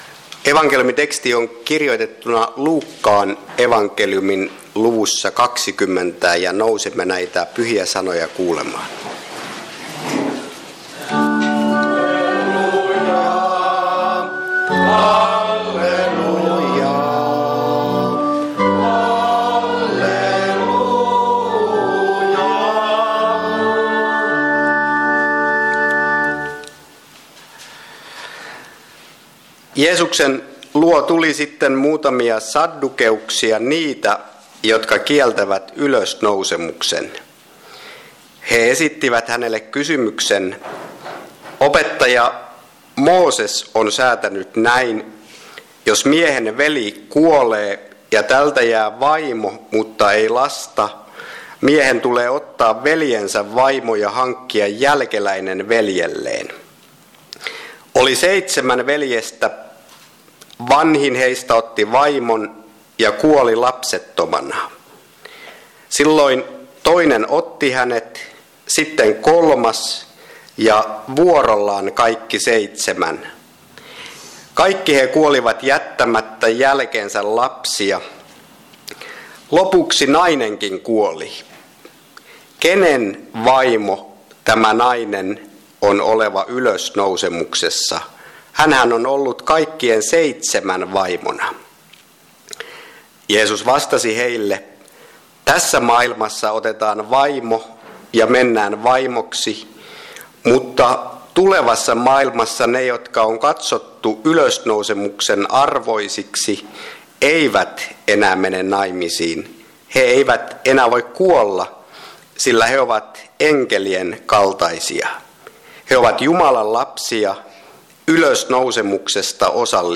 Kokoelmat: Seinäjoki Hyvän Paimenen kappelin saarnat